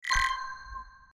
success.01f53302.mp3